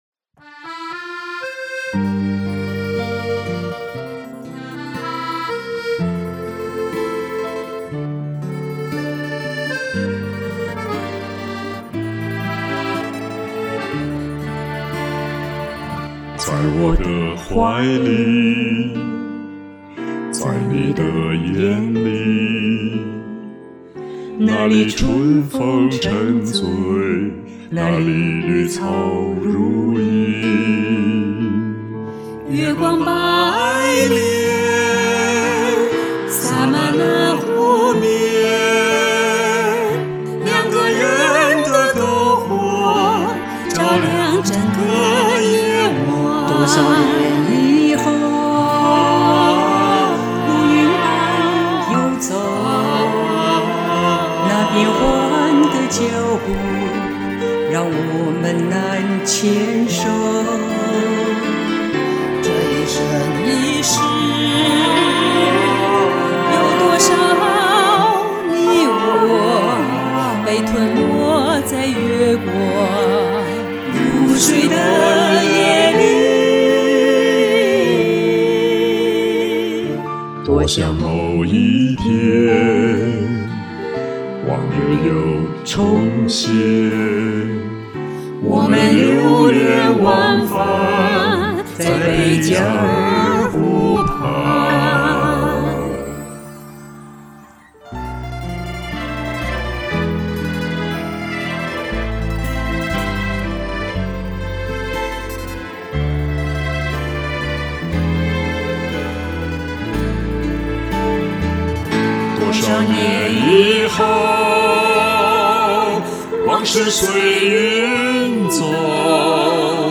4人的声音都有个很好的发挥，甚是完美！
中间多处有种无伴奏咏唱的效果，好听！
四个人的合唱